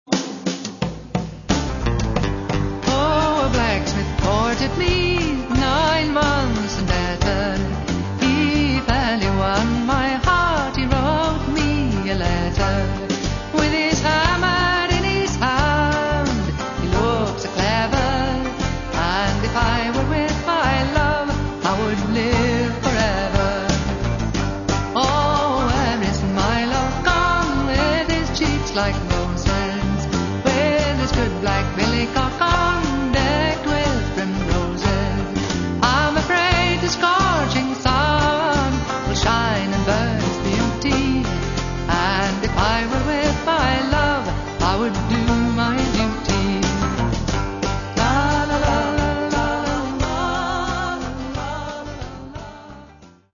First part, 0:52 sec, mono, 22 Khz, file size: 201 Kb.